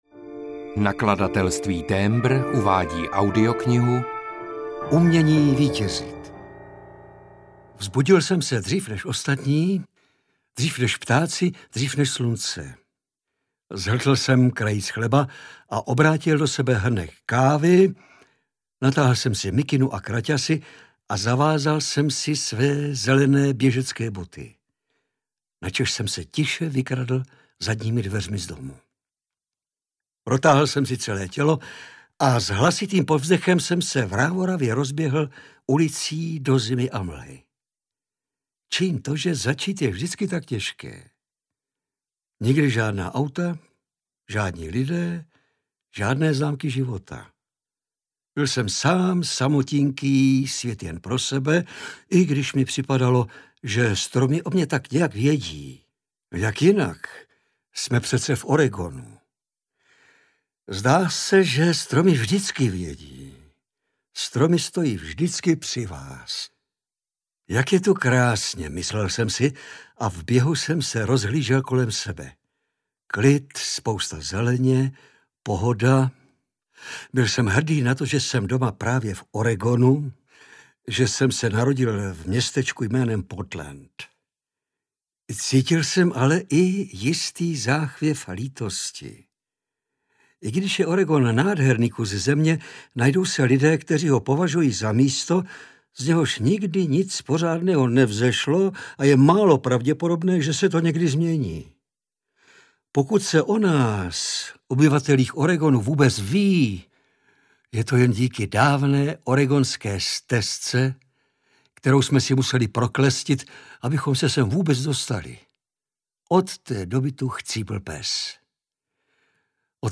Umění vítězit audiokniha
Ukázka z knihy
• InterpretLadislav Frej